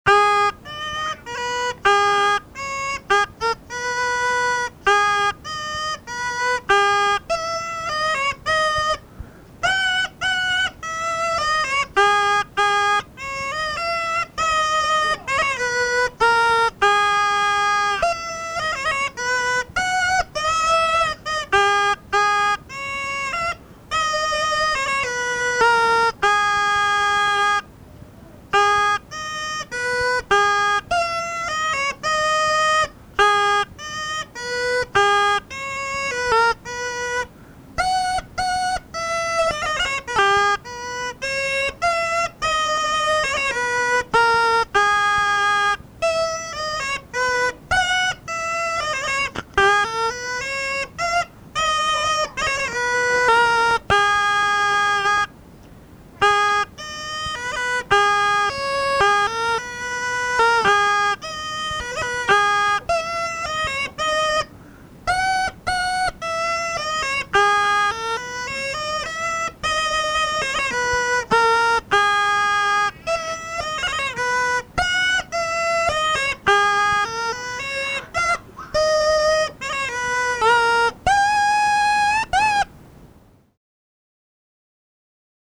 Голоса уходящего века (Курское село Илёк) Ой, при лужку, при лужку (рожок, инструментальная версия)